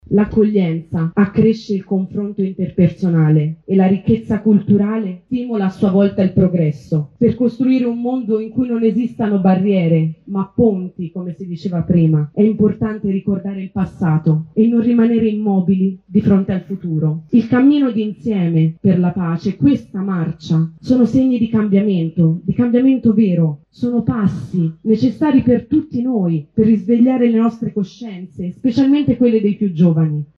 Si è svolta la marcia per la pace Perugia-Assisi, per dire ancora una volta no alla guerra e alle ingiustizie. Ascoltiamo la voce dei ragazzi, protagonisti dell’edizione di quest’anno.
sonoro-marcia-pace.mp3